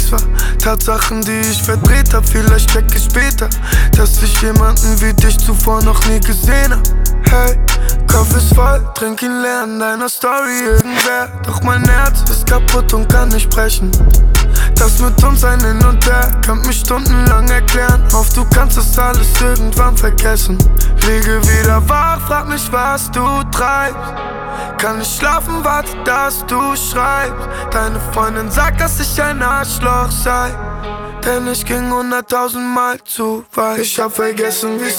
Жанр: Рэп и хип-хоп / Иностранный рэп и хип-хоп
# Hip-Hop